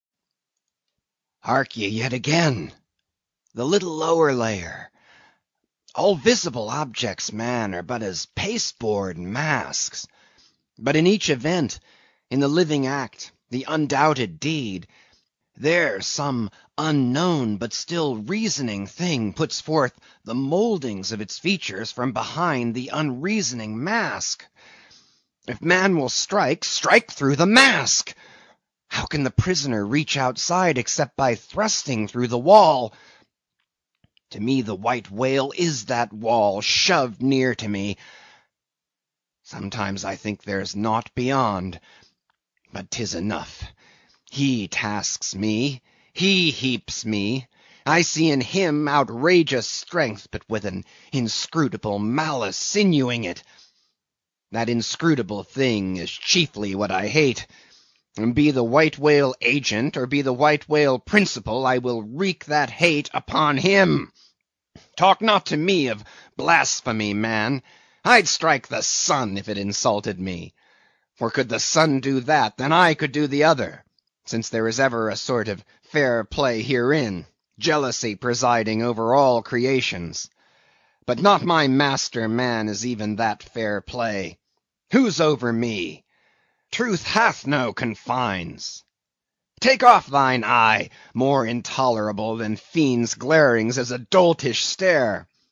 英语听书《白鲸记》第416期 听力文件下载—在线英语听力室